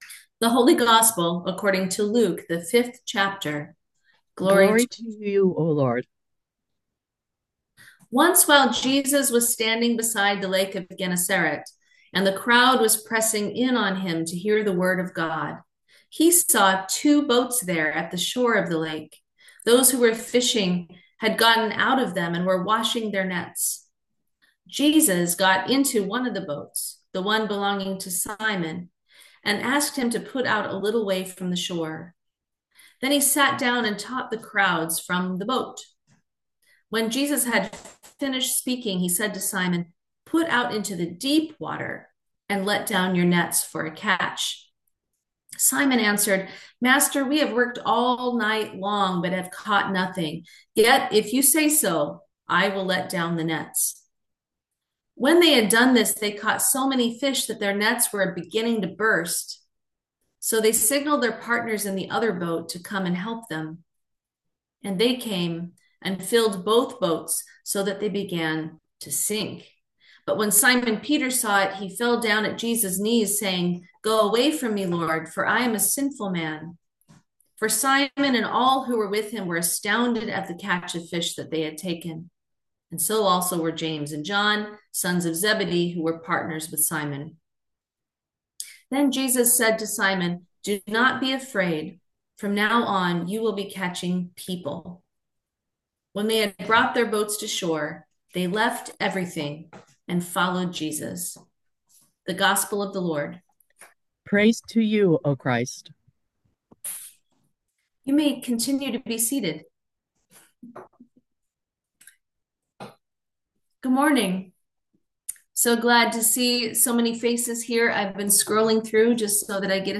Sermon for the Fifth Sunday after Epiphany 2025